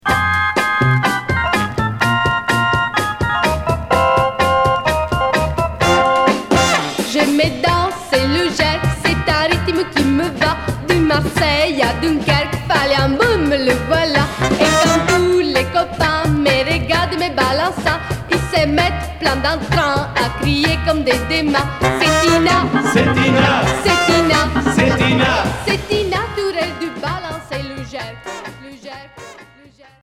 Chanteuse italienne qui chante en français sur cet EP